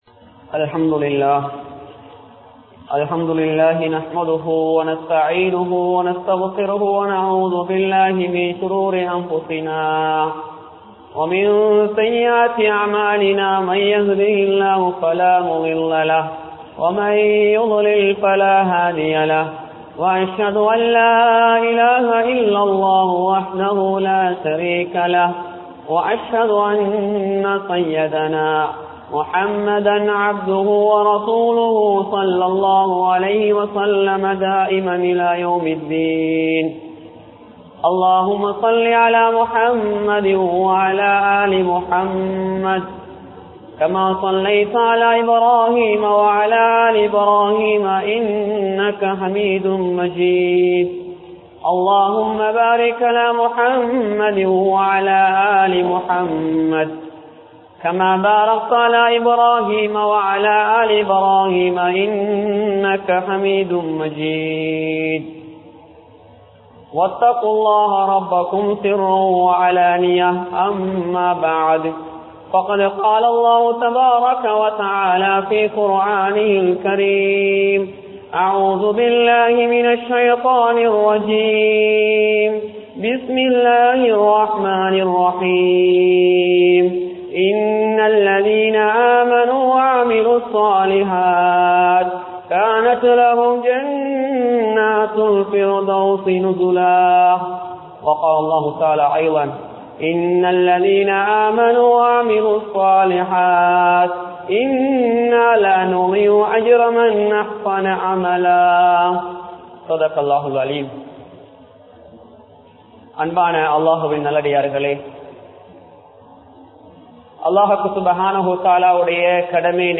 Siriya Amal Periya Kooli(சிறிய அமல் பெரிய கூலி) | Audio Bayans | All Ceylon Muslim Youth Community | Addalaichenai
Kurunegala, Hettipola Jumua Masjith